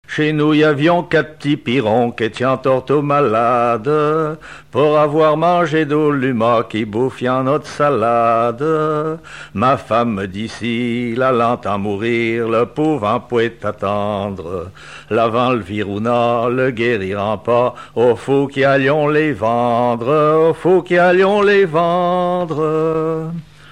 Genre strophique
Enquête Arexcpo en Vendée-Association Histoire et Traditions du Pays des Achards
Pièce musicale inédite